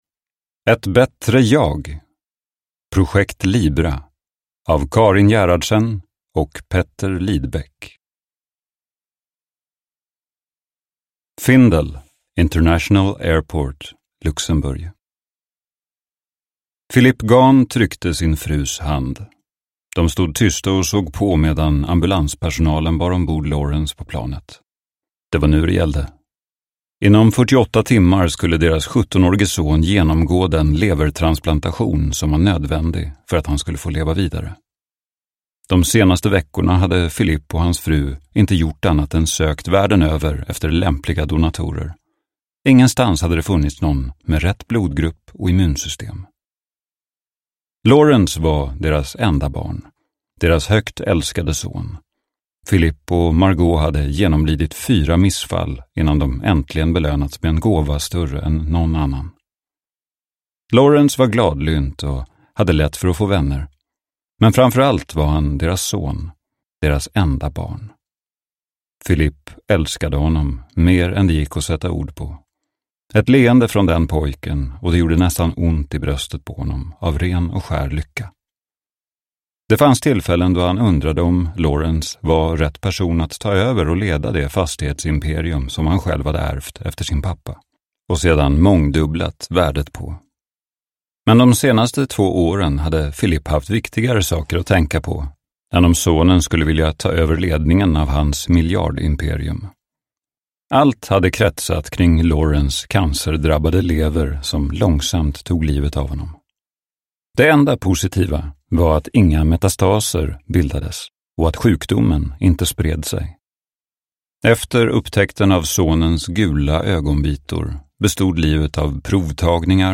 Ett bättre jag : Projekt Libra – Ljudbok – Laddas ner